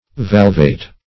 Valvate \Valv"ate\, a. [L. valvatus having folding doors.]